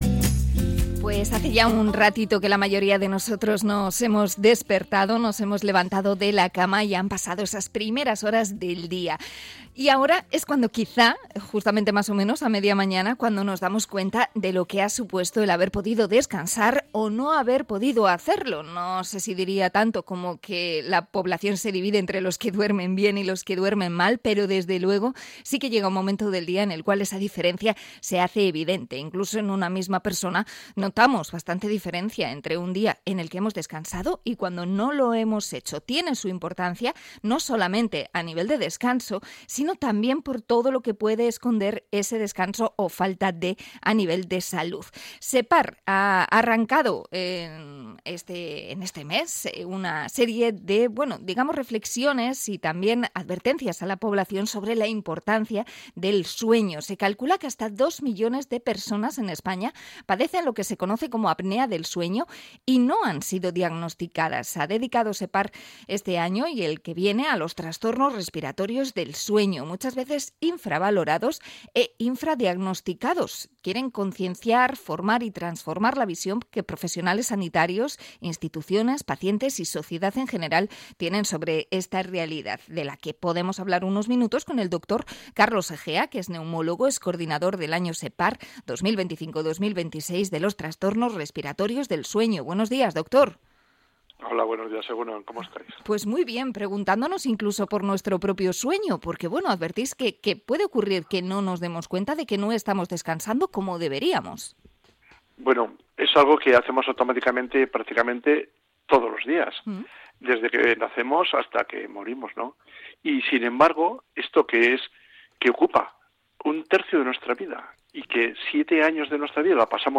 Entrevista a SEPAR por los trastornos del sueño